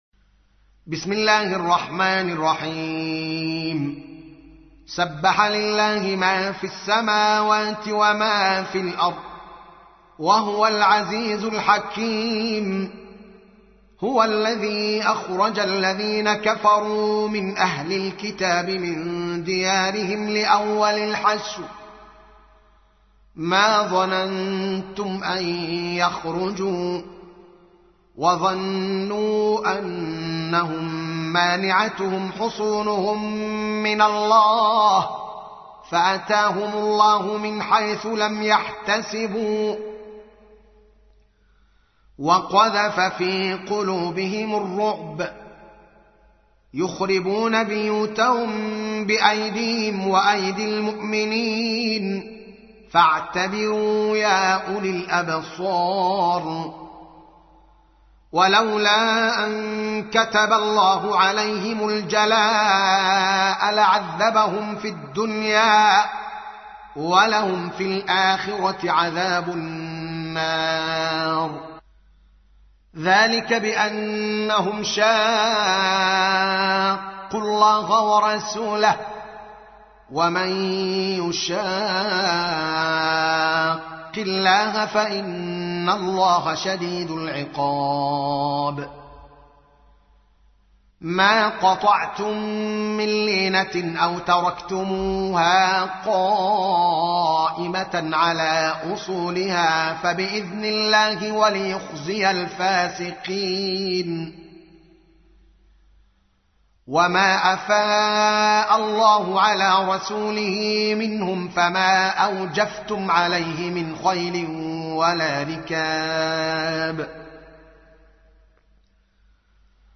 59. سورة الحشر / القارئ